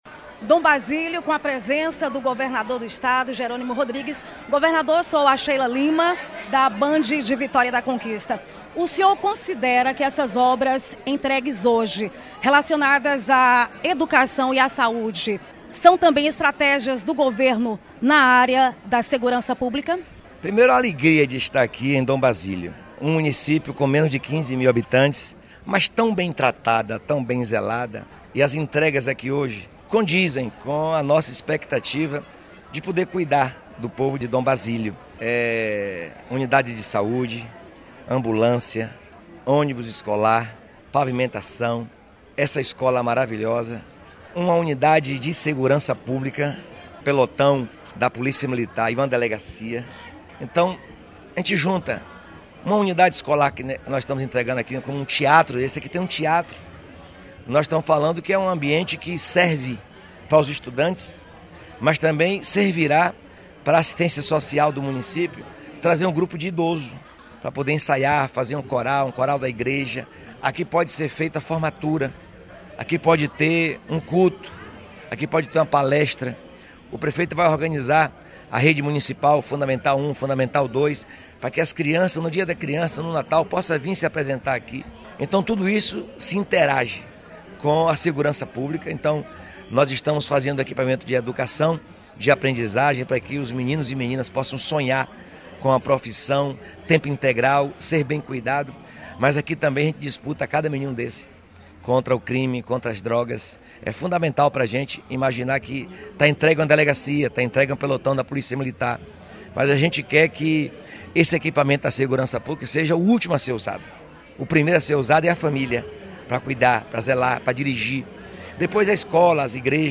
Em entrevista a Band FM Jerônimo Rodrigues ainda falou sobre o combate a dengue e as eleições municipais desse ano